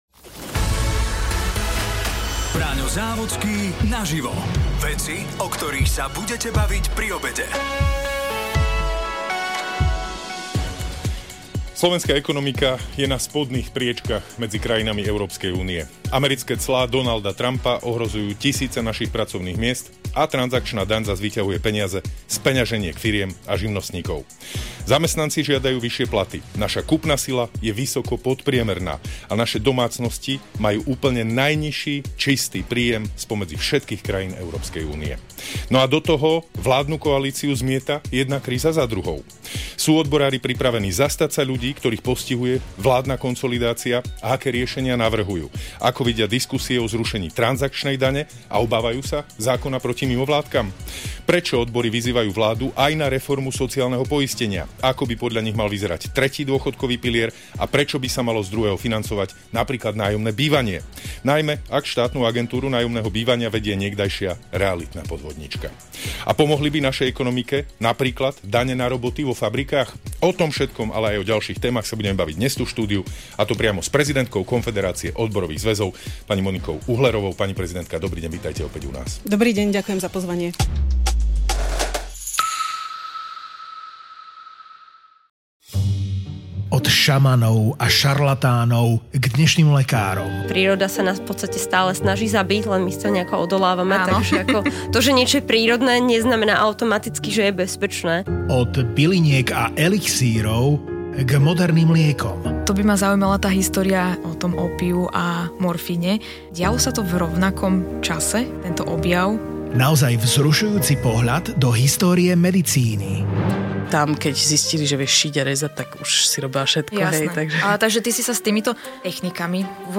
Braňo Závodský sa rozprával s prezidentkou Konfederácie odborových zväzov Monikou Uhlerovou .